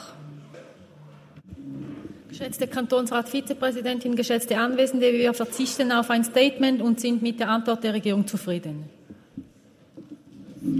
20.2.2024Wortmeldung
Session des Kantonsrates vom 19. bis 21. Februar 2024, Frühjahrssession